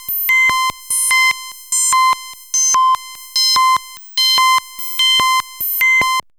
RHYTHM FM 1.wav